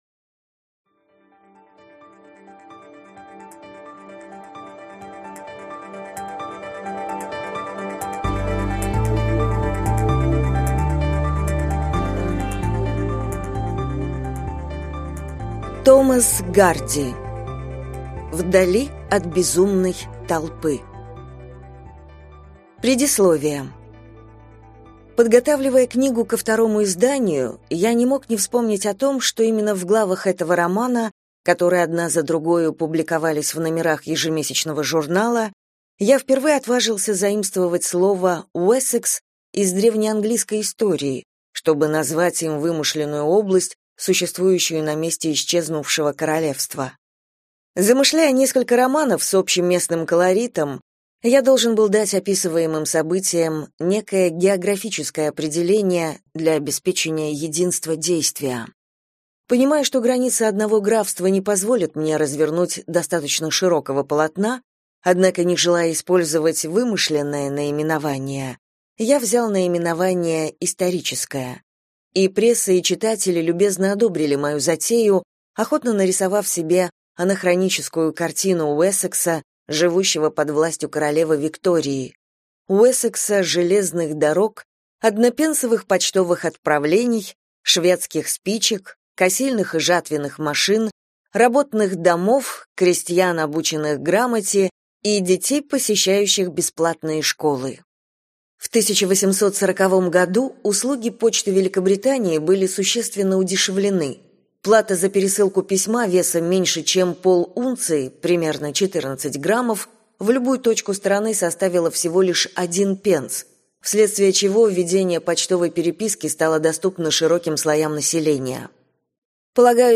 Аудиокнига Вдали от безумной толпы | Библиотека аудиокниг